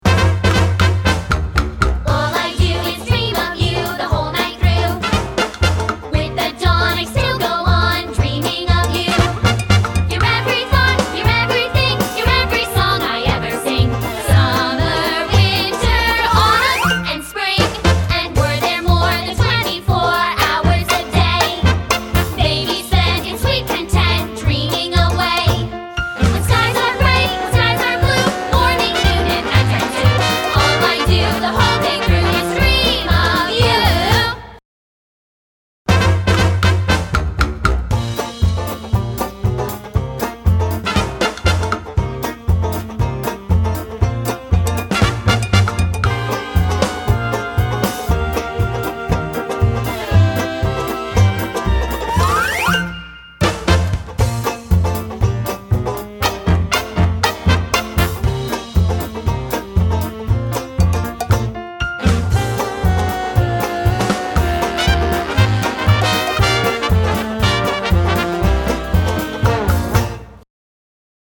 Audition Songs
Each mp3 clip has the song with guide vocals and then without.